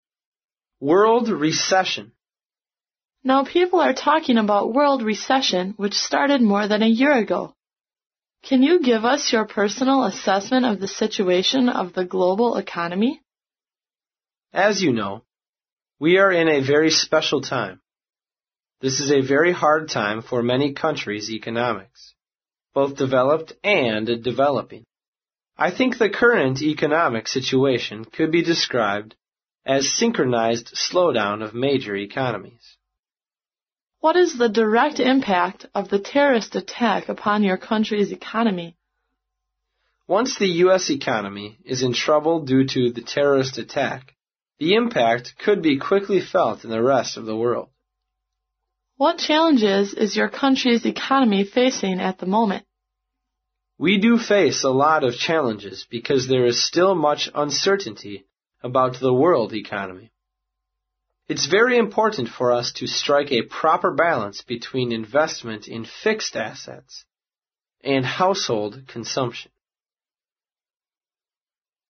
在线英语听力室外贸英语话题王 第49期:世界经济衰退的听力文件下载,《外贸英语话题王》通过经典的英语口语对话内容，学习外贸英语知识，积累外贸英语词汇，潜移默化中培养英语语感。